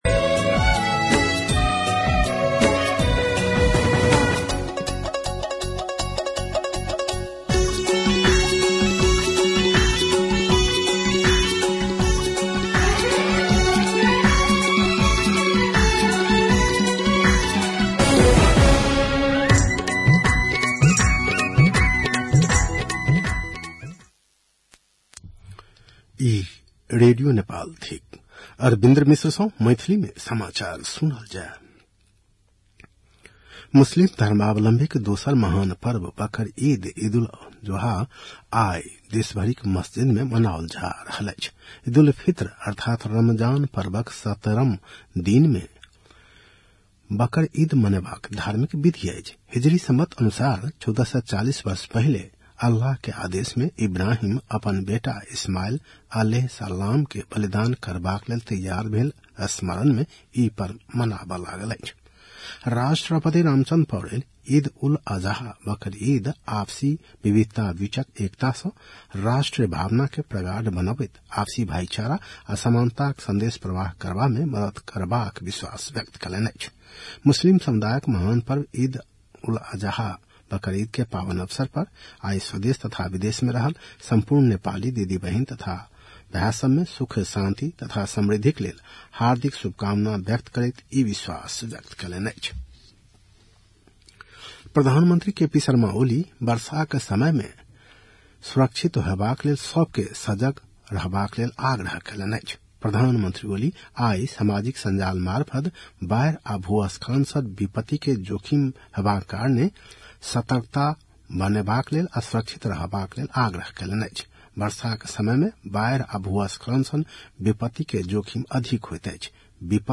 मैथिली भाषामा समाचार : २४ जेठ , २०८२
6-pm-maithali-news-.mp3